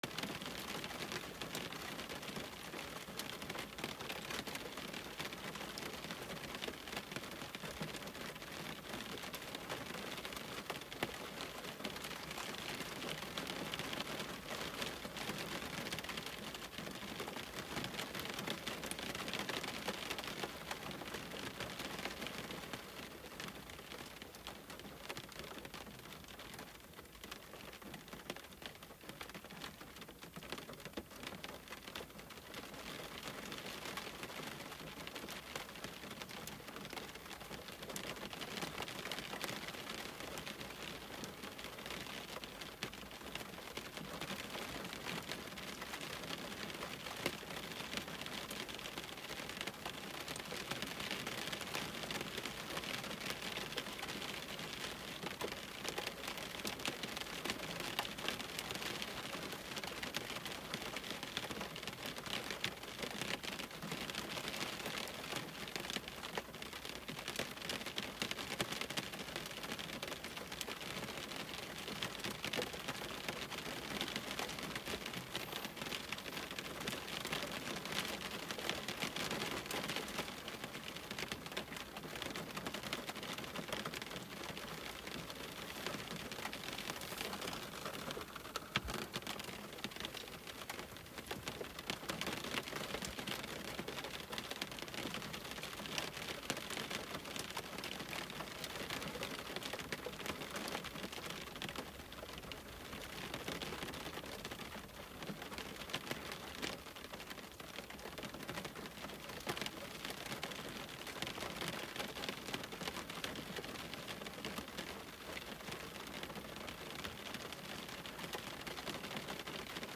Index of /Relaxing/Nature/Rain/